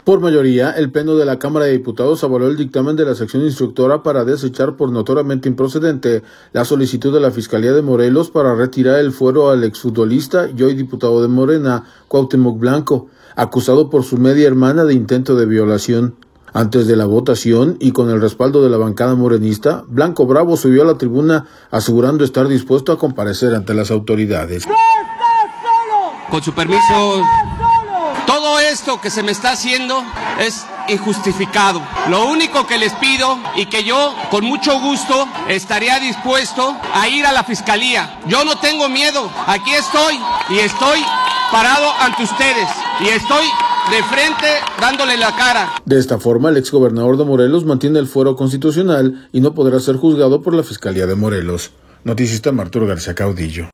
Antes de la votación y con el respaldo de la bancada morenista, Blanco Bravo subió a la tribuna asegurando estar dispuesto a comparecer ante las autoridades.